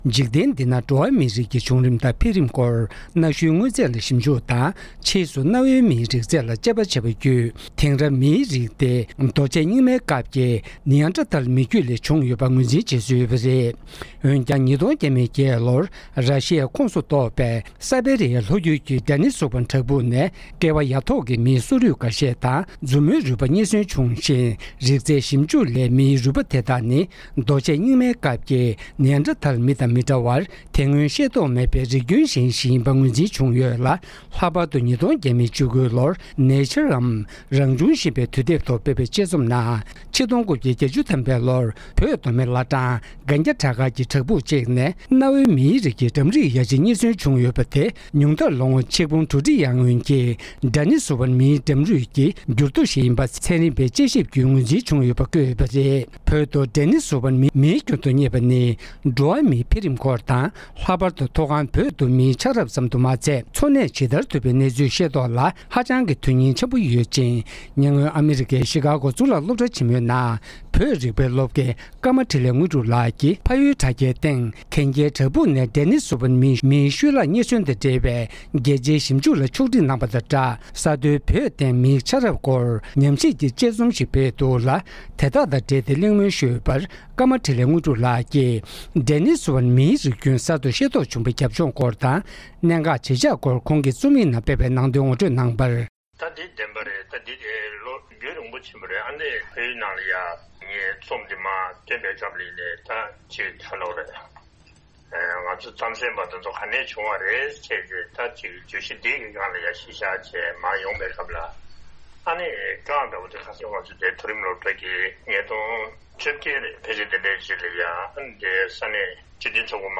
བཅར་འདྲི་ཕྱོགས་བསྒྲིགས་ཞུས་པའི་དུམ་བུ་དང་པོ་འདིར་གསན་རོགས་ཞུ༎